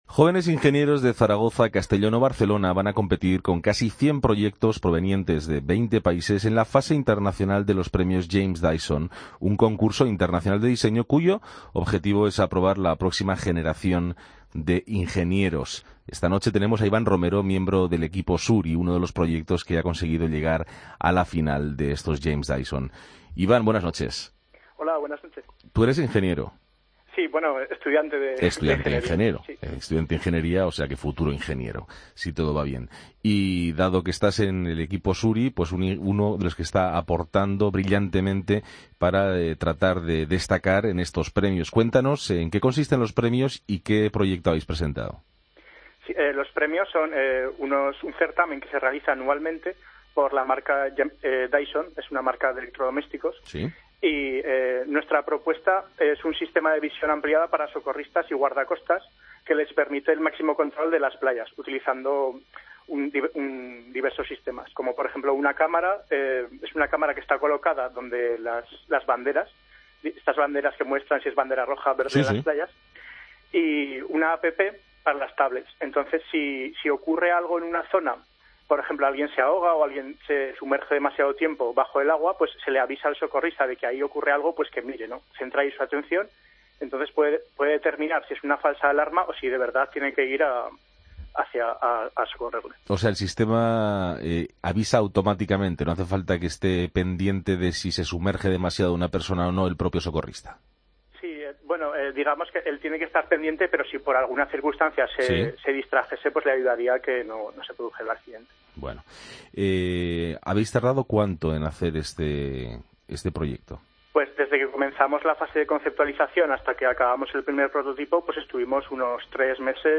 AUDIO: Tres ingenieros españoles compiten en el consurso internacional al mejor diseño. Tenemos con nosotros a dos de los competidores...